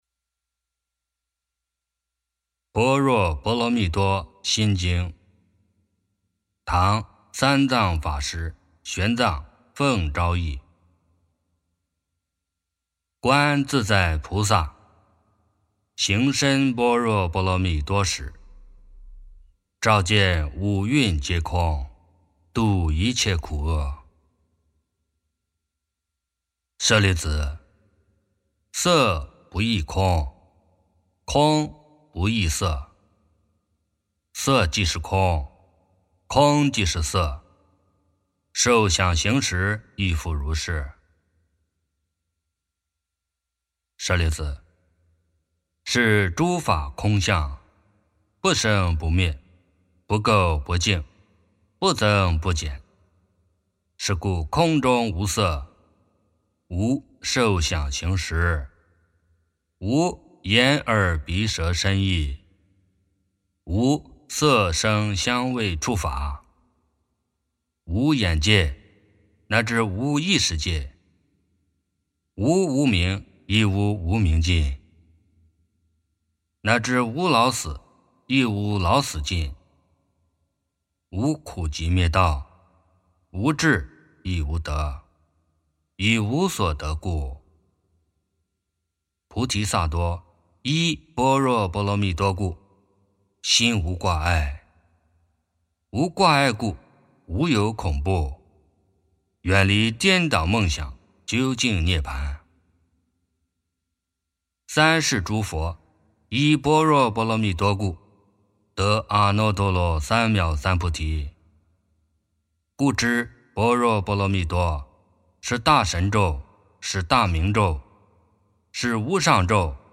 般若波罗蜜多心经.清净 - 诵经 - 云佛论坛